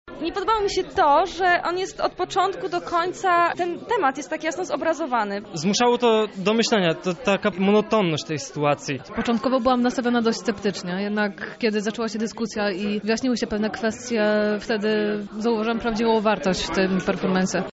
Widzowie-performance.mp3